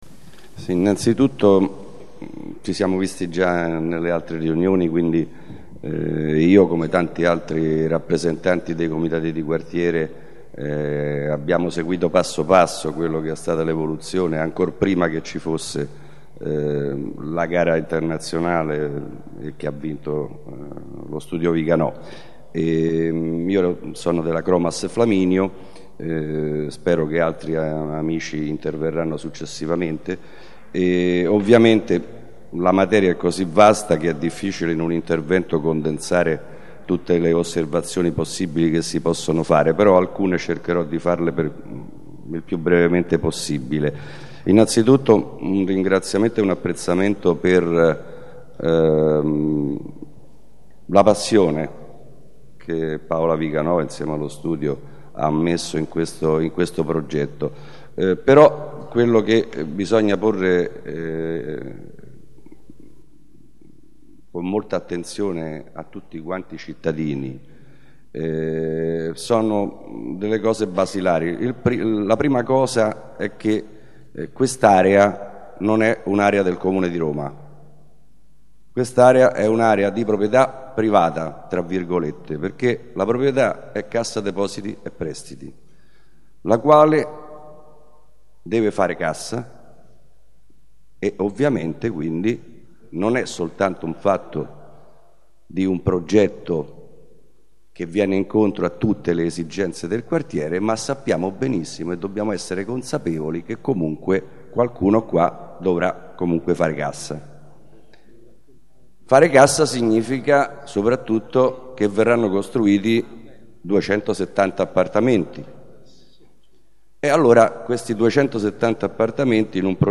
Piano di recupero del Quartiere Città della Scienza - Ascolto audio del secondo incontro partecipativo